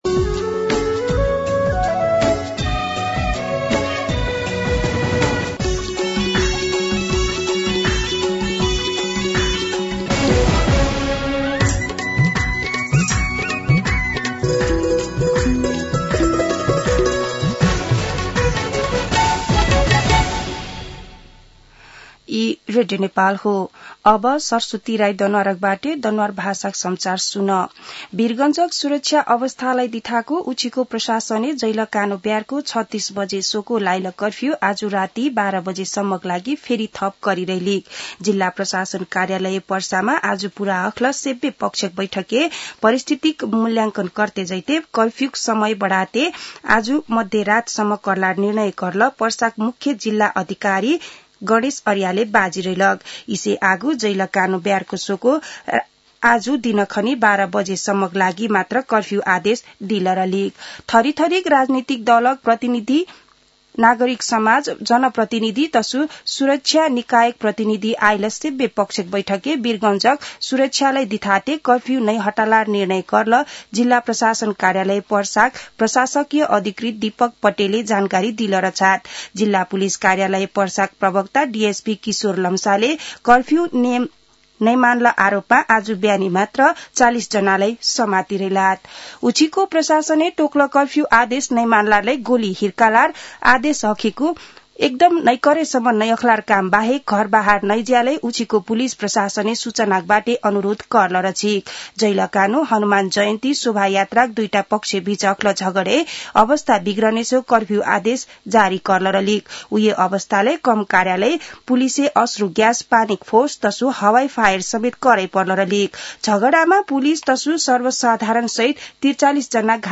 दनुवार भाषामा समाचार : ३१ चैत , २०८१
Danuwar-News-31.mp3